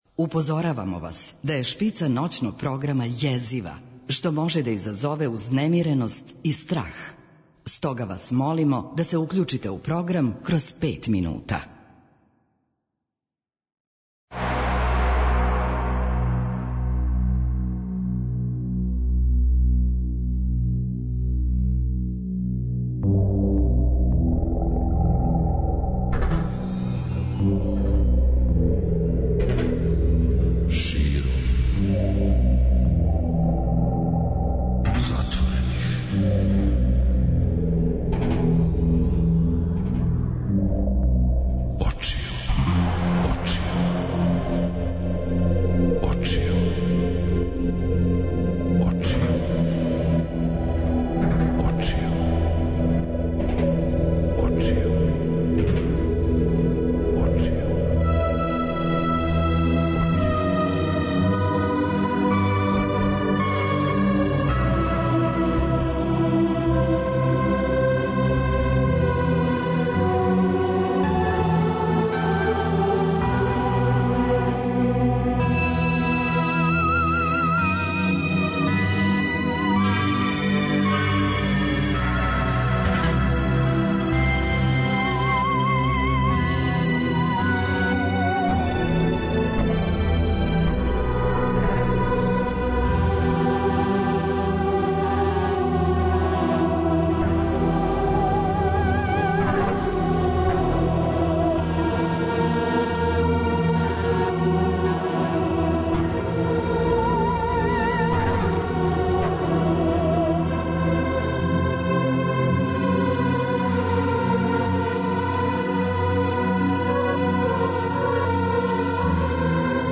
У ноћном програму Београда 202 Широм затворених очију дочекаћемо Божић и испратити Бадње вече у свечарском, радосном расположењу јер сви са радошћу дочекујемо највећи православни празник.